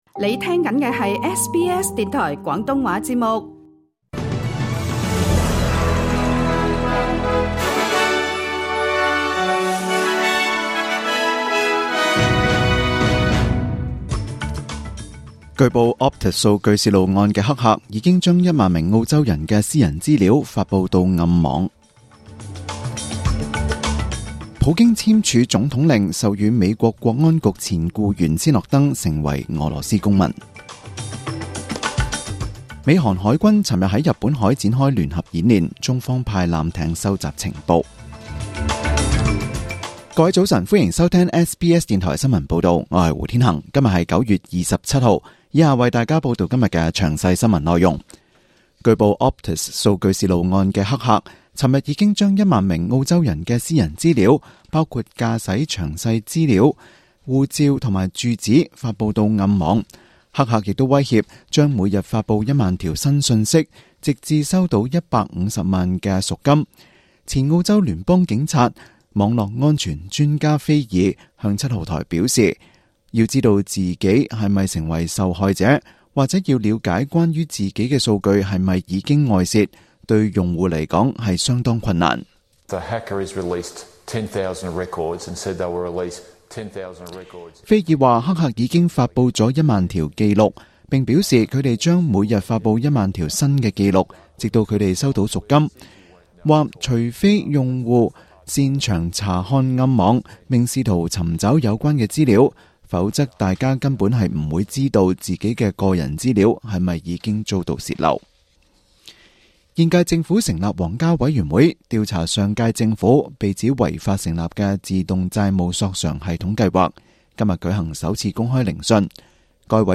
SBS電台廣東話節目中文新聞 Source: SBS / SBS News